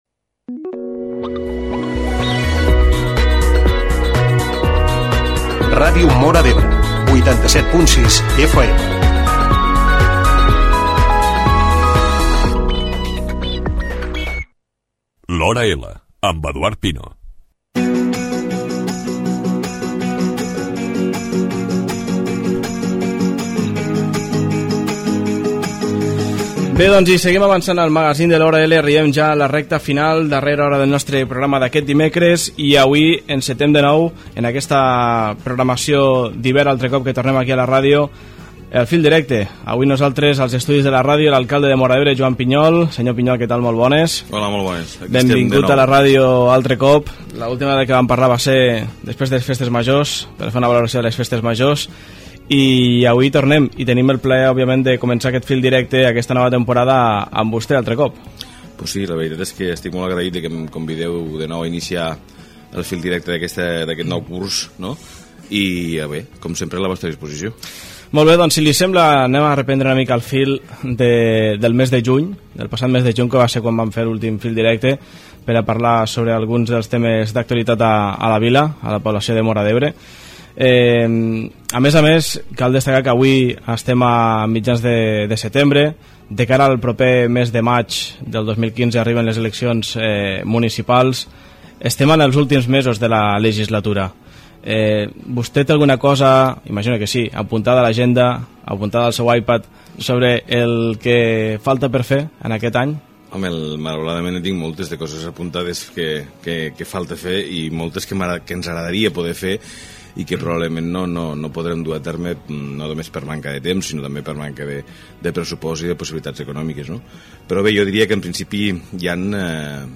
Amb l’alcalde de Móra d’Ebre, Joan Piñol.